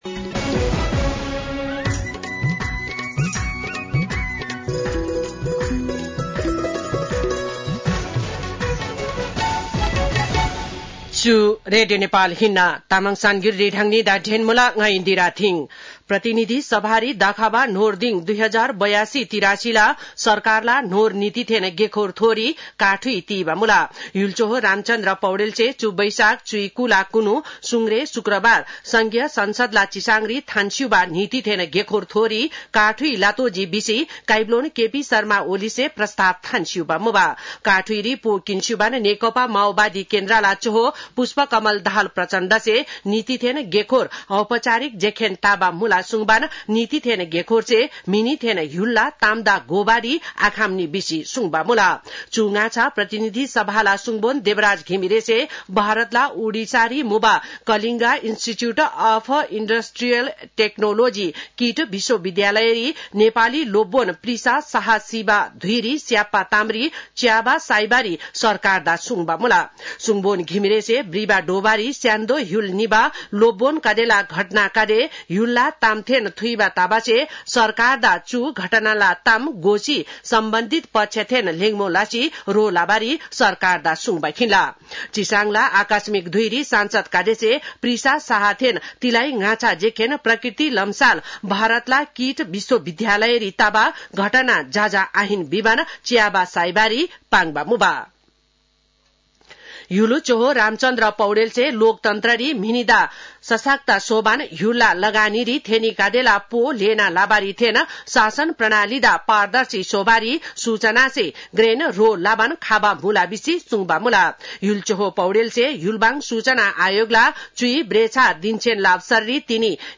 तामाङ भाषाको समाचार : २२ वैशाख , २०८२